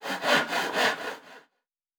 pgs/Assets/Audio/Fantasy Interface Sounds/Wood 15.wav at master
Wood 15.wav